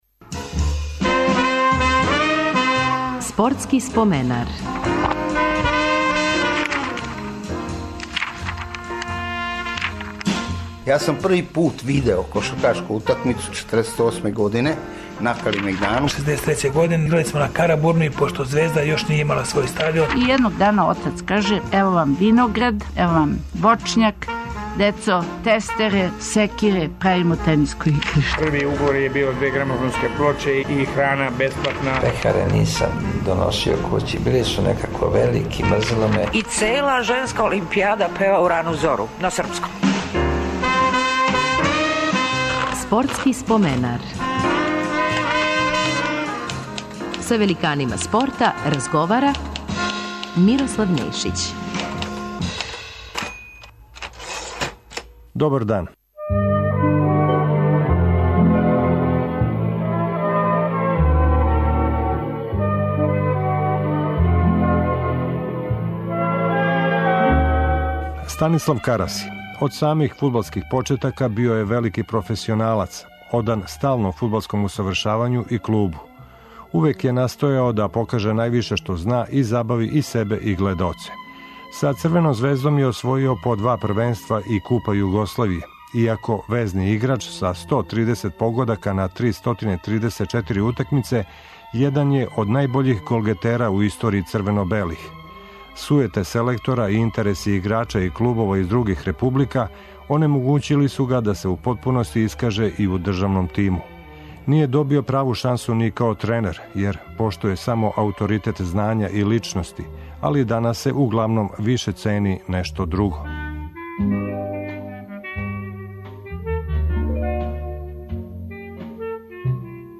Гост нам је фудбалер Станислав Караси.